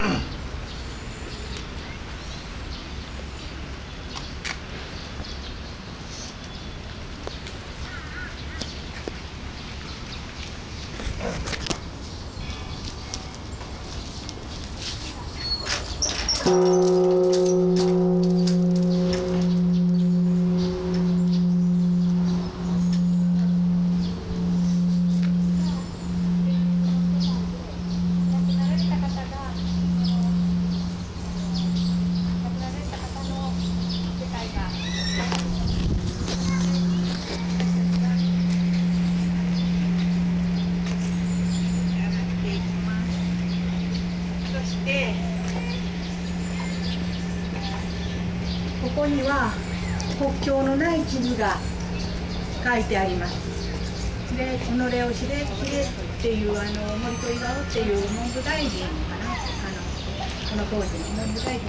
bell of peace 2
bell-of-peace-2.wav